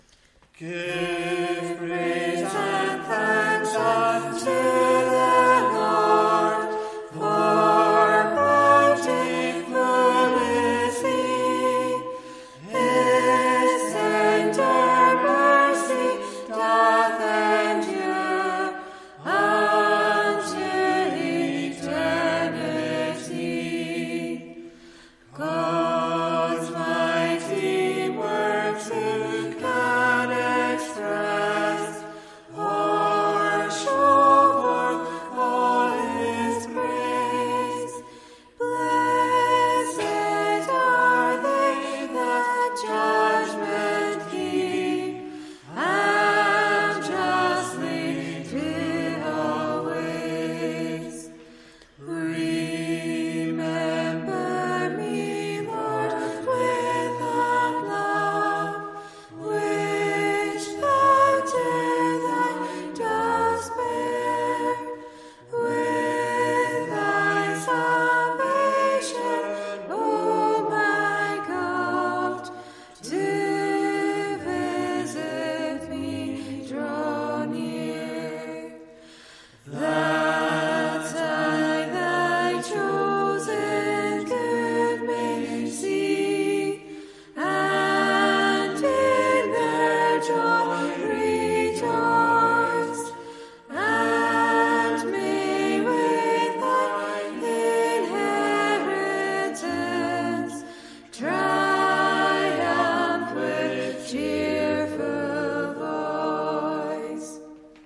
Congregational Psalm Singing
Recorded during the first lockdown when church services went on-line, with five of us singing live at a microphone built for one.